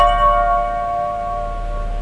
Crystal Sound Effect
crystal.mp3